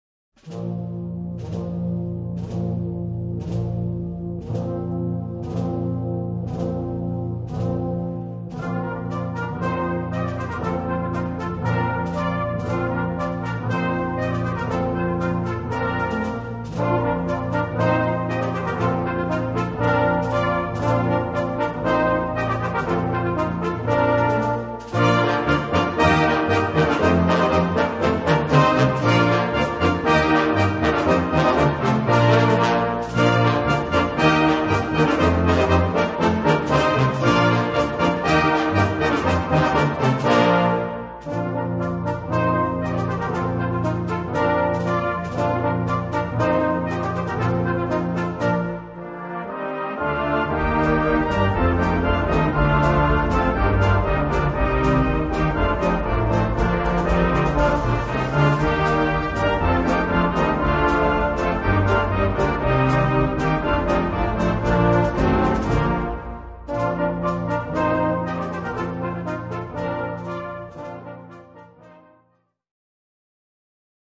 A5-Quer Besetzung: Blasorchester Zu hören auf